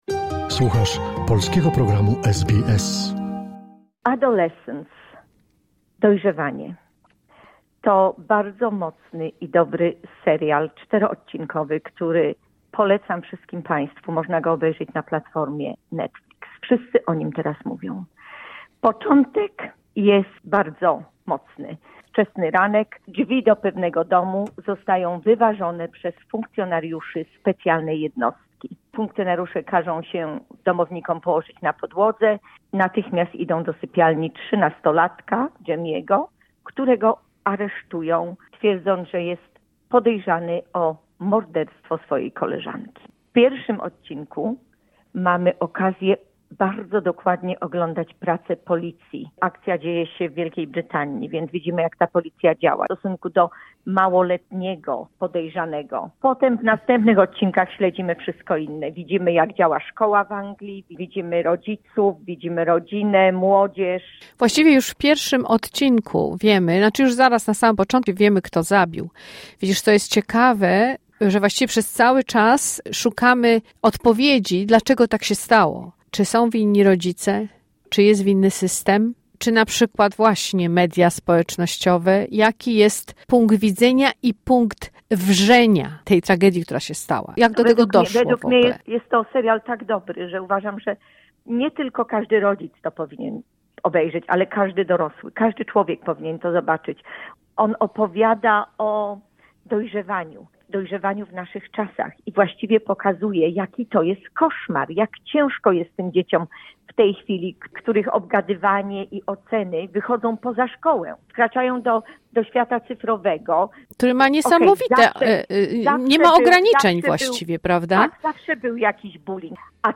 "Adolescence" czyli "Dojrzewanie" - recenzja filmowa